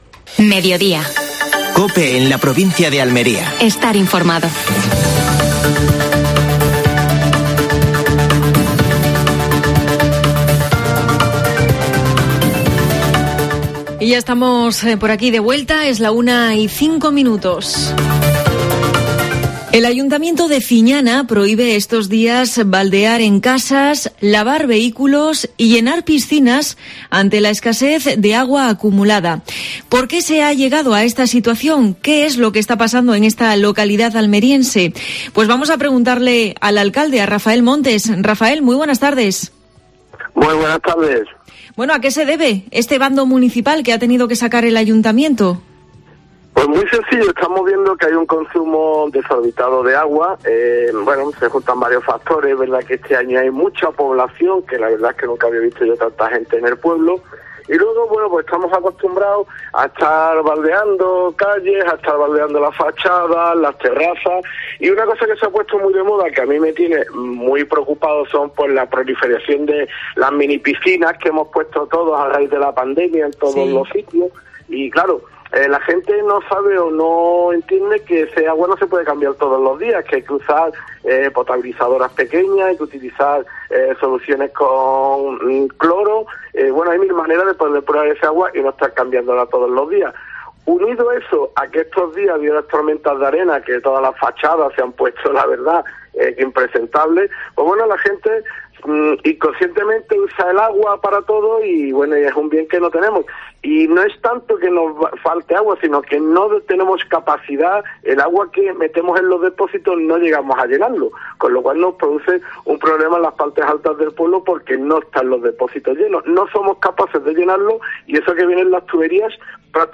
AUDIO: Actualidad en Almería. Entrevista a Rafael Montes (alcalde de Fiñana). Última hora deportiva. ¿Qué pasó tal día como hoy en la provincia?